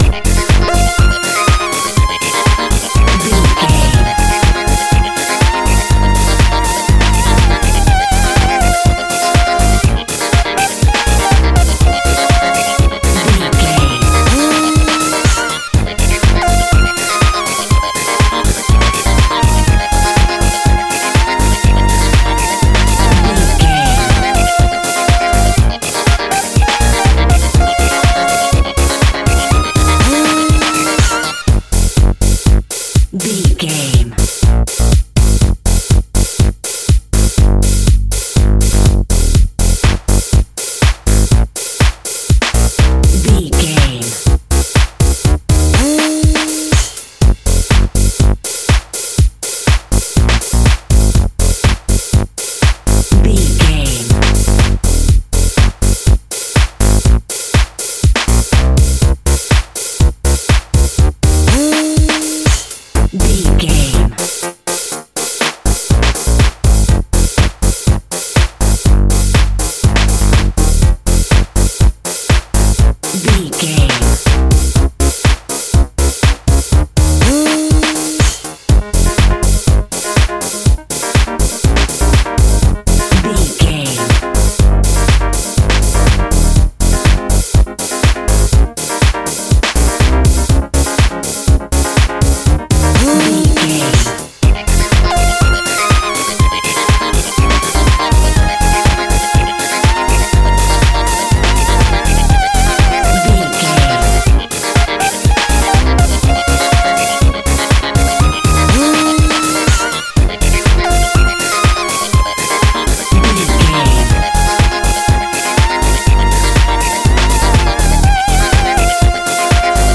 Aeolian/Minor
F#
groovy
uplifting
driving
energetic
drum machine
synthesiser
electronic
disco
deep house
upbeat
uptempo
electronic drums
synth lead
synth bass